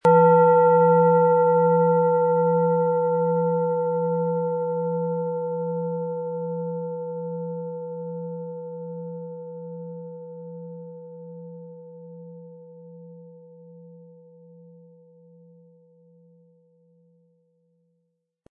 Tibetische Universal-Klangschale, Ø 16,9 cm, 500-600 Gramm, mit Klöppel
Wir haben diese Schale beim Aufnehmen angespielt und den subjektiven Eindruck, dass sie alle Körperregionen gleich stark anspricht.
Unter dem Artikel-Bild finden Sie den Original-Klang dieser Schale im Audio-Player - Jetzt reinhören.
MaterialBronze